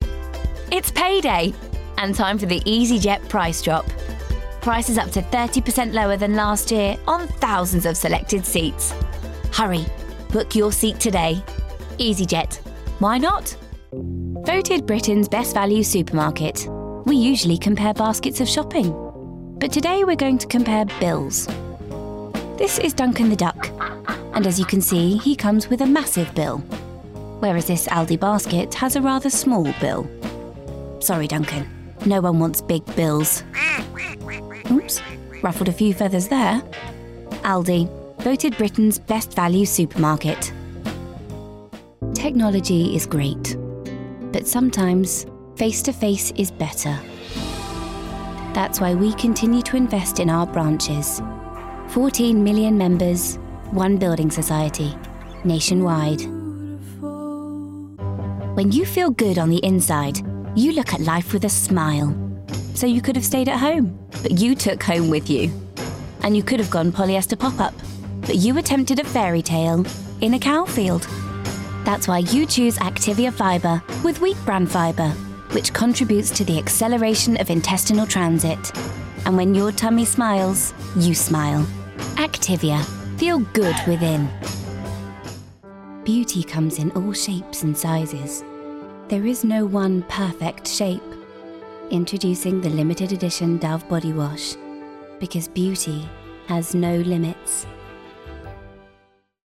Anglais (britannique)
Fiable
Chaleureux
De la conversation